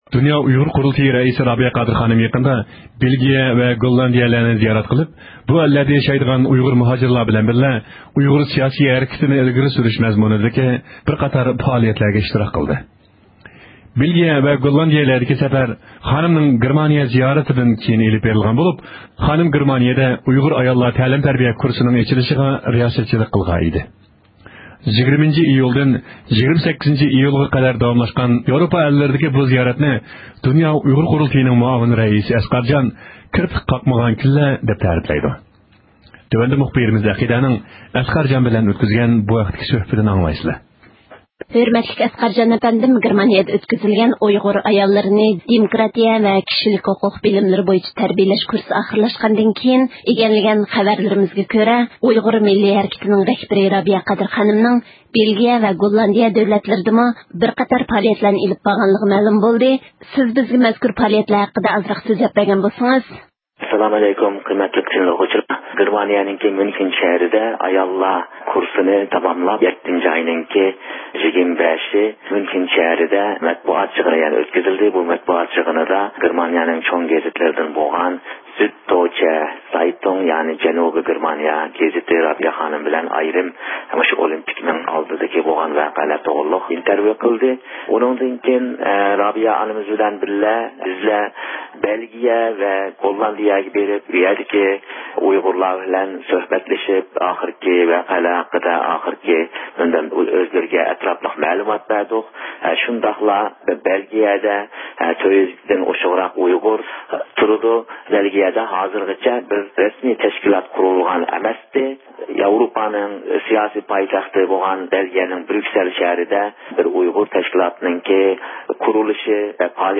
يۇقىرىدىكى ئاۋاز ئۇلىنىشىدىن، مۇخبىرىمىز